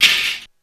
Crash.wav